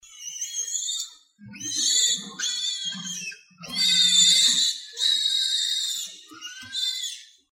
ChiXuJiaoSheng.mp3